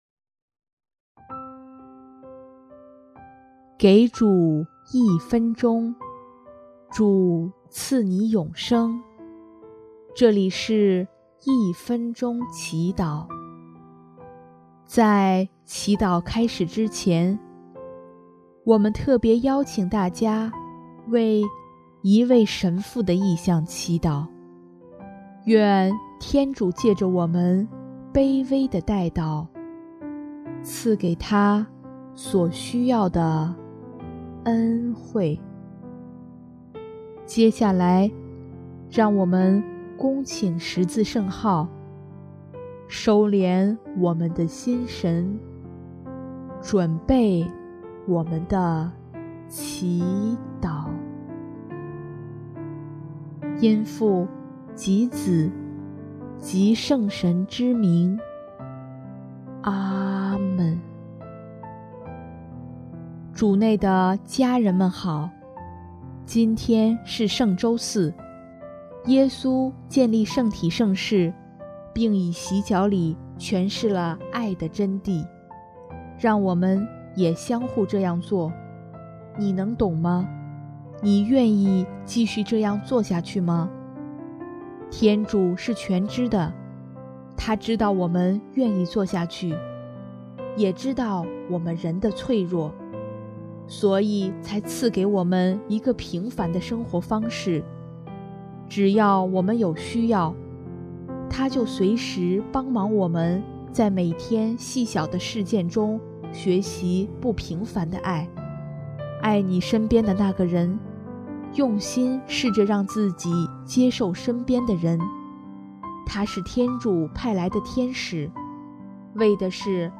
音乐： 第二届华语圣歌大赛参赛歌曲《慈爱的目光》（一位神父：求主帮自己度过难关）